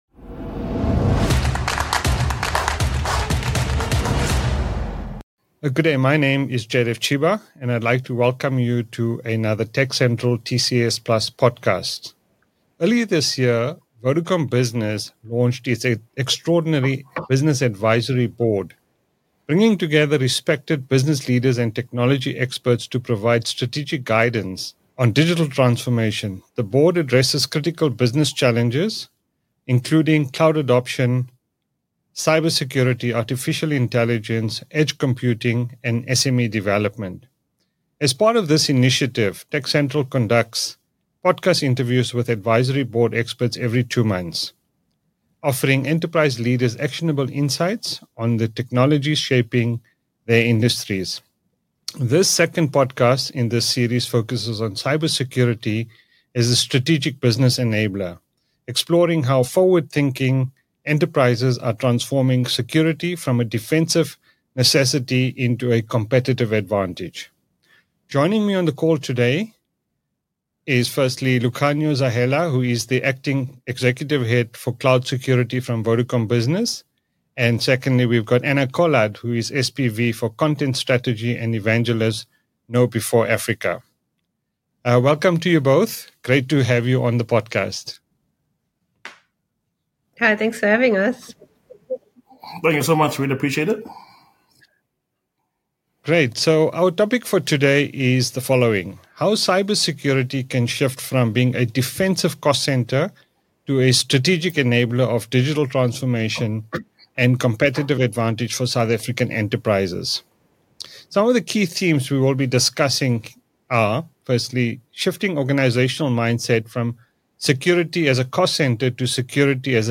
TechCentral TCS+ podcast discussion